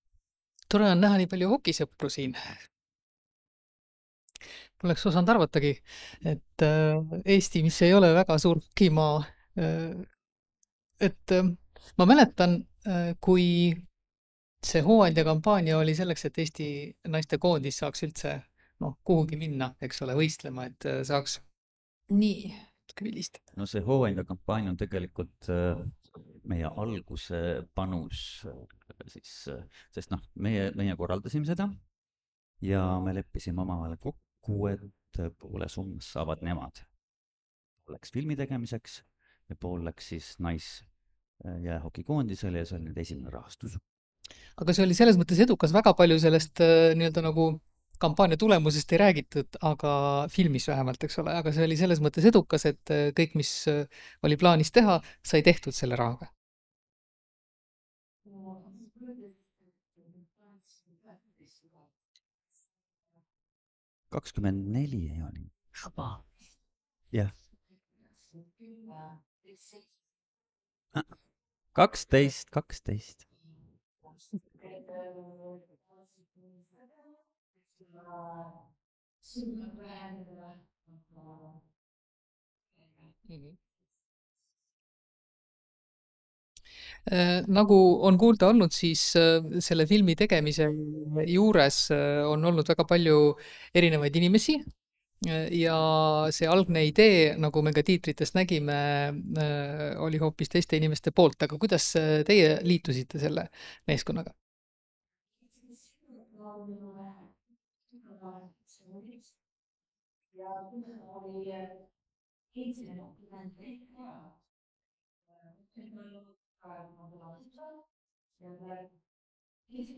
22. veebruaril 2026 linastus dokumentaalfilm “Hõbekontsadel”, mis vaatleb Eesti naiste hokikoondise lugu. Pärast filmi vestlesid